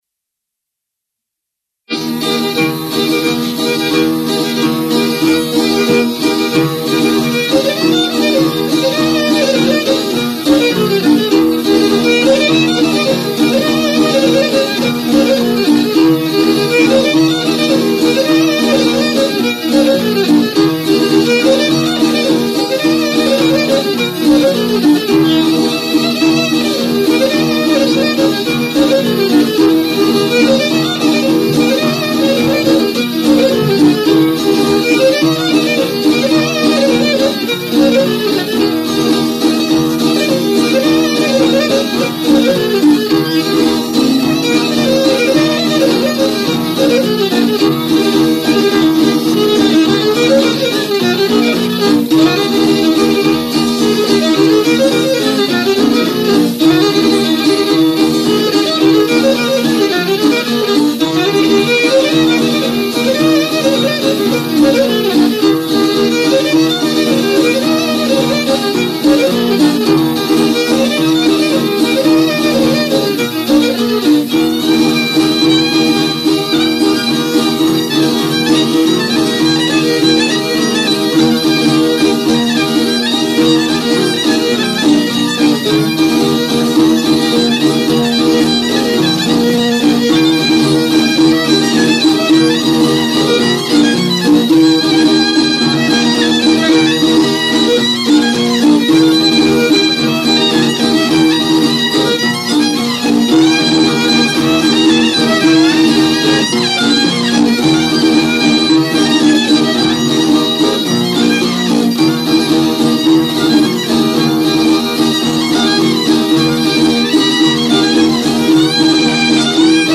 ΜΟΥΣΙΚΗ ΑΠΟ ΤΗΝ ΠΑΡΑΔΟΣΗ ΤΗΣ ΑΝΑΤΟΛΙΚΗΣ ΚΡΗΤΗΣ
3. Σητειακές κοντυλιές στη Re maggiore